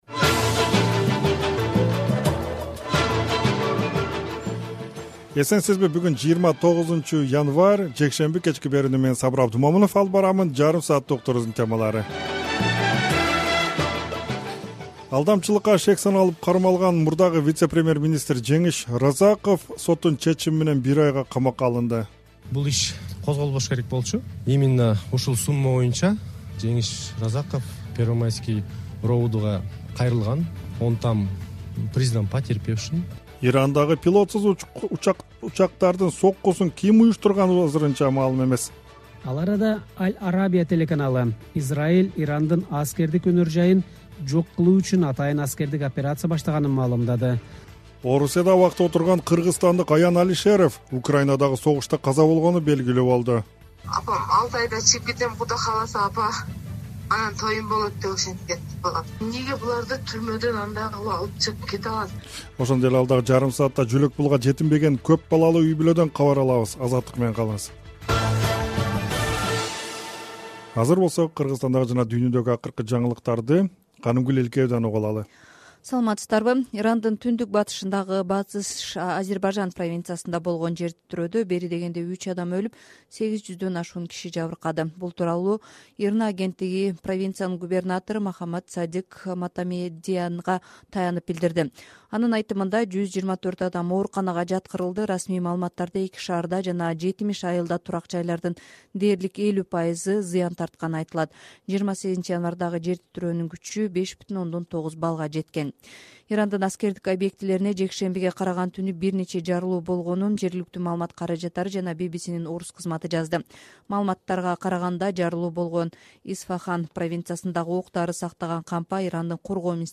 Кечки радио эфир | 29.01.2023 | Ирандын аскердик заводуна чабуул жасалды